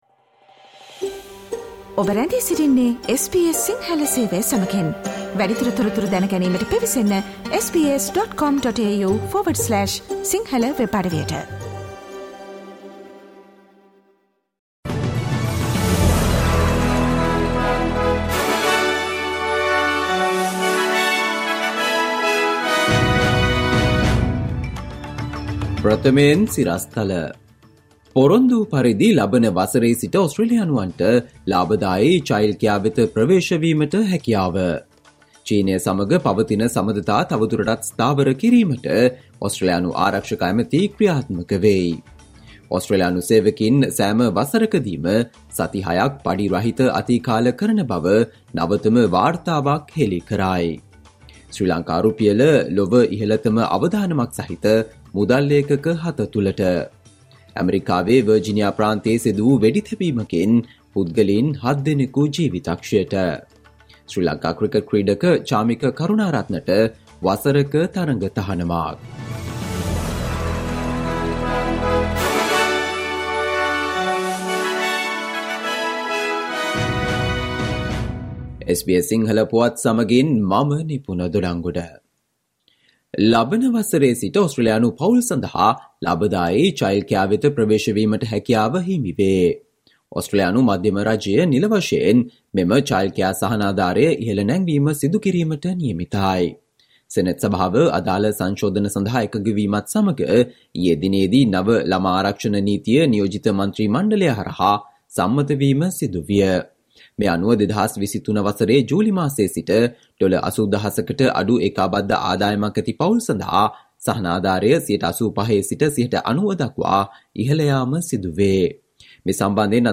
Listen to the SBS Sinhala Radio news bulletin on Thursday, 24 November 2022